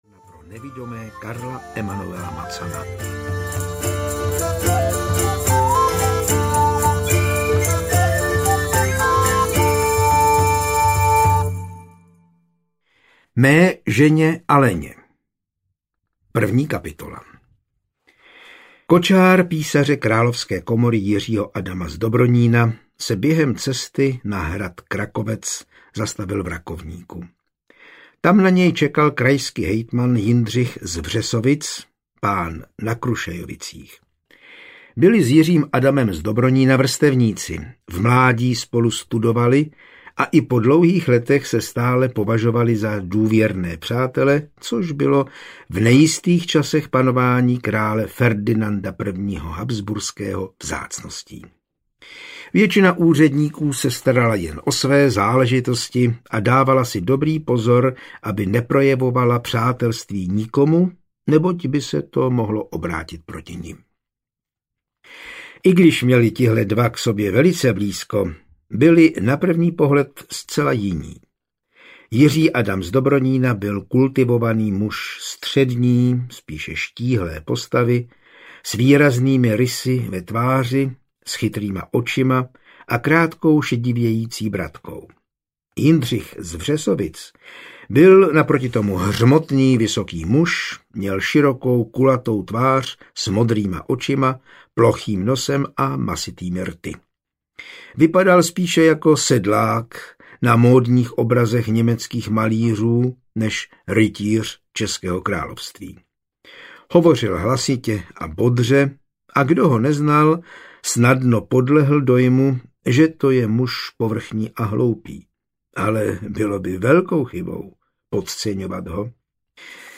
Otrávený pohár audiokniha
Ukázka z knihy
otraveny-pohar-audiokniha